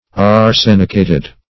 Arsenicated - definition of Arsenicated - synonyms, pronunciation, spelling from Free Dictionary
arsenicated.mp3